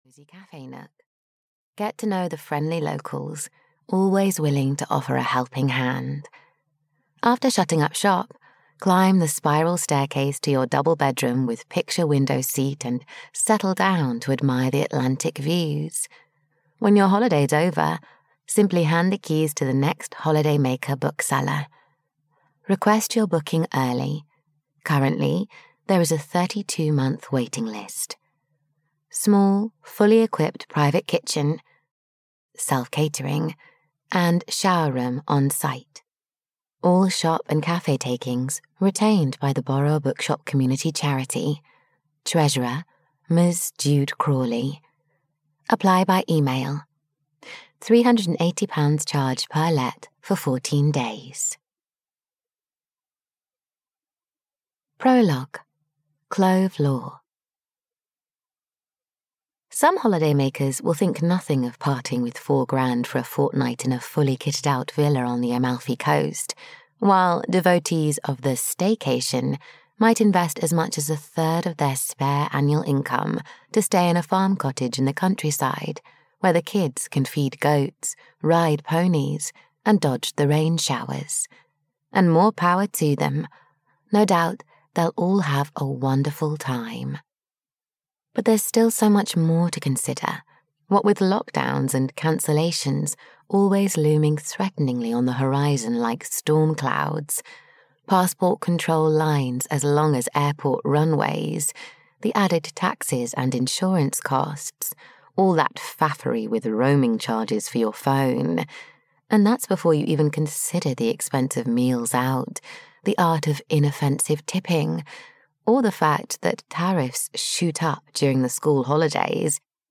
Christmas at the Borrow a Bookshop Holiday (EN) audiokniha
Ukázka z knihy